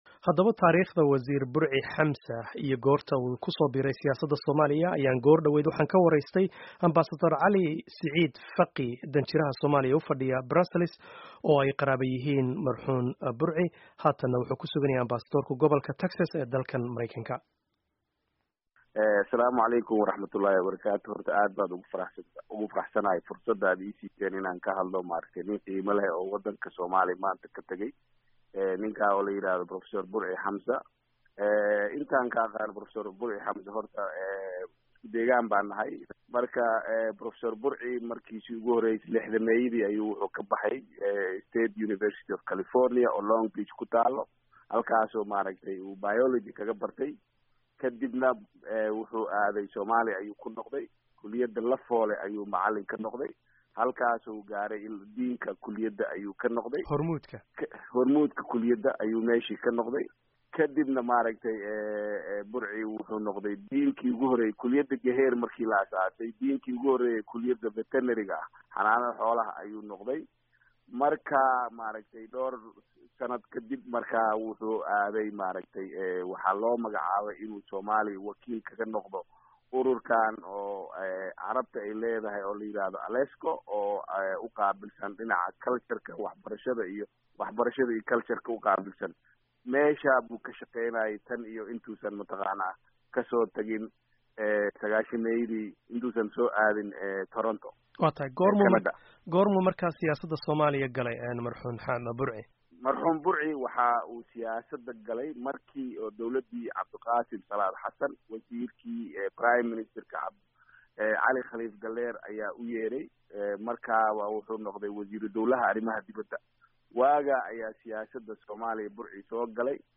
Dhageyso wareysiga Danjire Cali Fiqi oo ka hadlaya taariikhdii Wasiir Burci Xamza.
Wareysi: Taariikhdii Burci Xamza